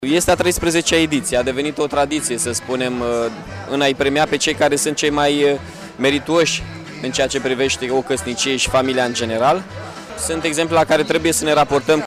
Viceprimarul Mihai Chirica a precizat că manifestarea este la a 13-a ediție și în Parcul Expoziției au fost invitate peste 350 de familii dar numai 50 îndeplinesc criterii pentru a fi premiate: